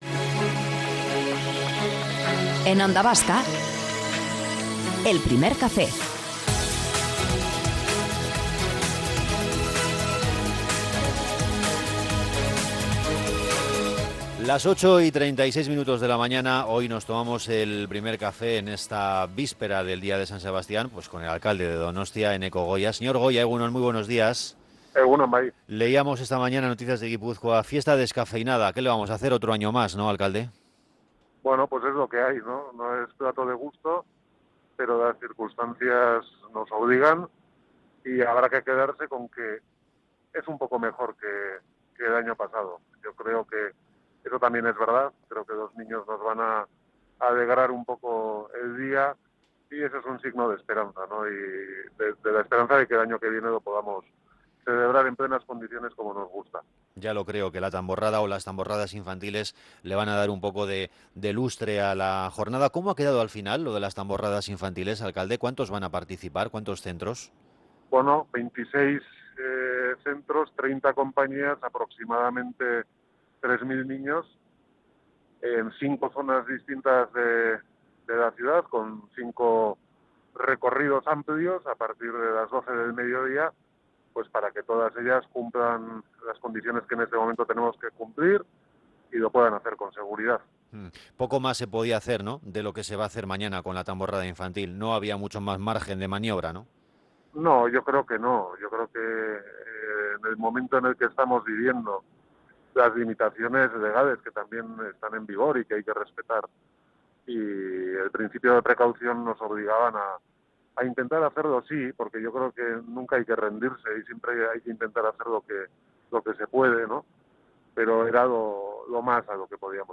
Hablamos con el alcalde de Donostia la víspera del día de San Sebastián - Onda Vasca
Morning show conectado a la calle y omnipresente en la red.